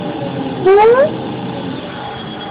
Machine sound Ringtone
Sound Effects
effects , sfx , gear , machine , forklift ,